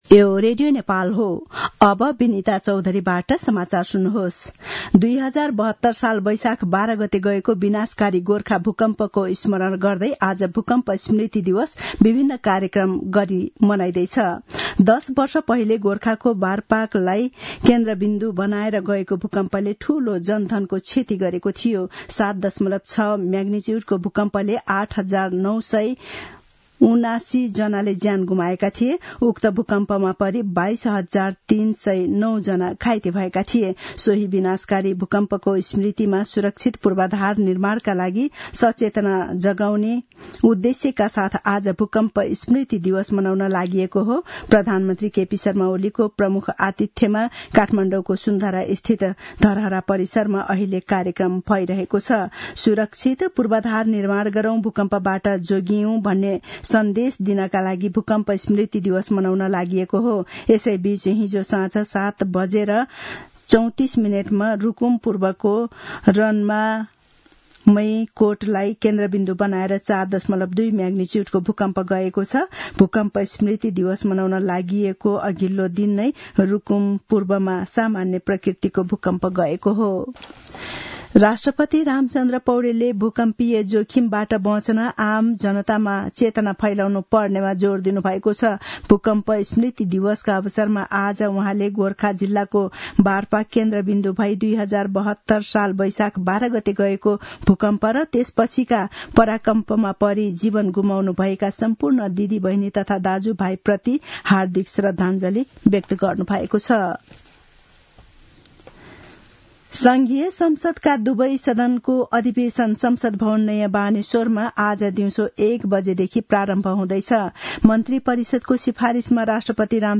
An online outlet of Nepal's national radio broadcaster
मध्यान्ह १२ बजेको नेपाली समाचार : १२ वैशाख , २०८२